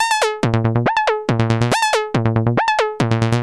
描述：他是一个滚动的酸性循环，使用免费的低音383合成器，即TB303仿真器创建。
标签： 140 bpm Acid Loops Synth Loops 590.67 KB wav Key : Unknown
声道立体声